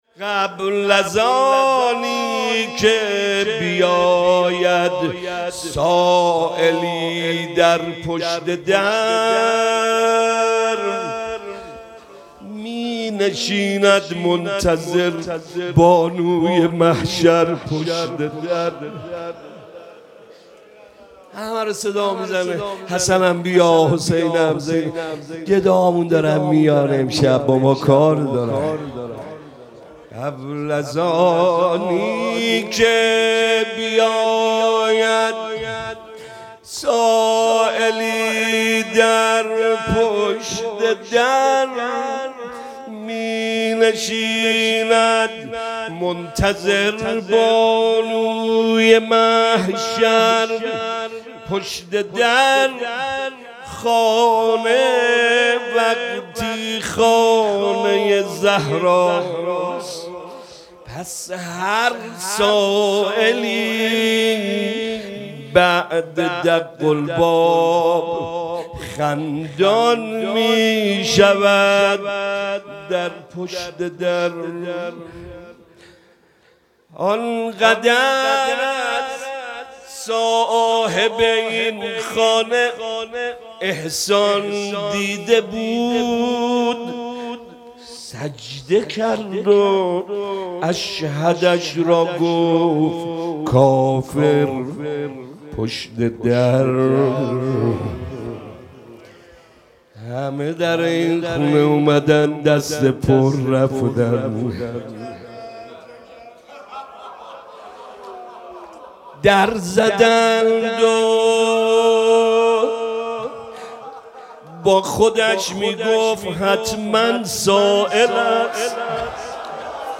روضه حضرت زهرا سلام‌الله‌علیها – محتوانشر